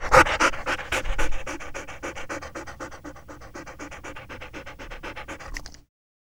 Index of /90_sSampleCDs/E-MU Producer Series Vol. 3 – Hollywood Sound Effects/Human & Animal/Dogs
PANT   SL00L.wav